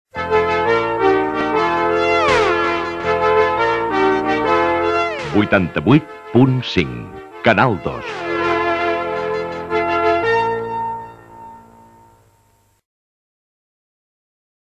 Indicatiu de l'emissora als 88.5 FM.